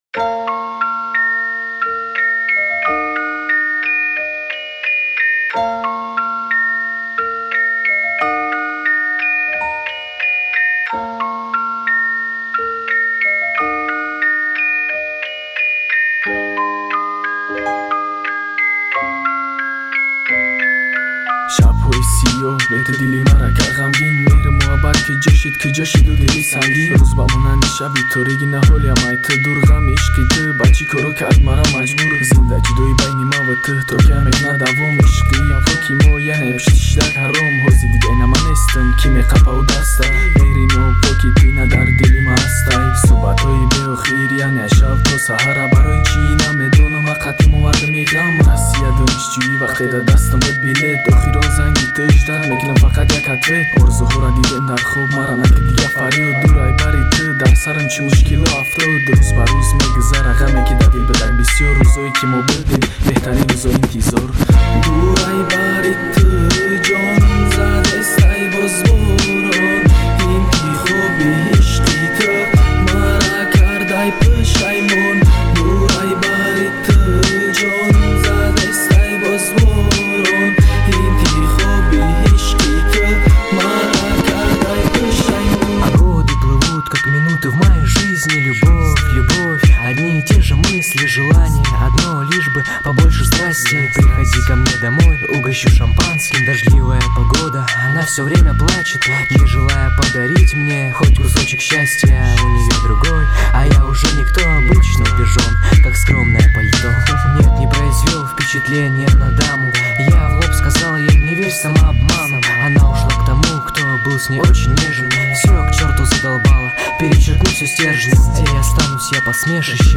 Главная » Файлы » Каталог Таджикских МР3 » Тадж. Rap